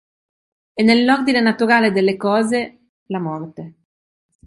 Произносится как (IPA)
/ˈkɔ.ze/